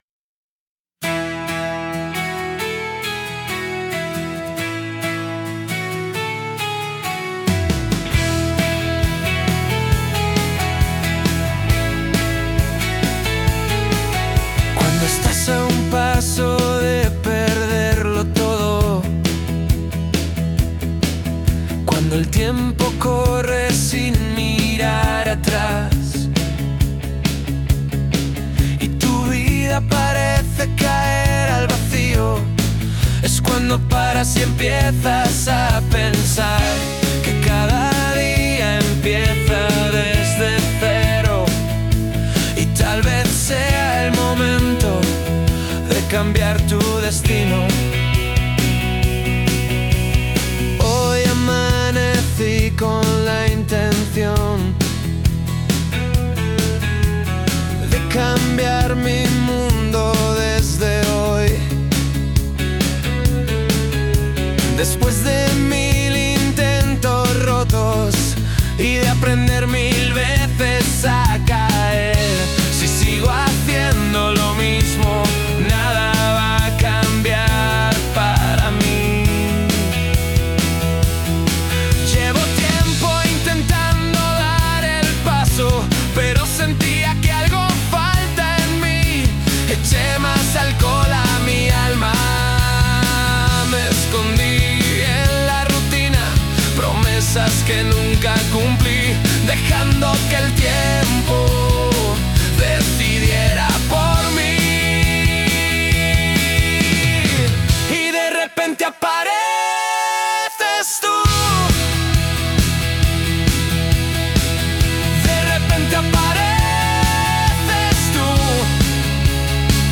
Genre Pop Emocional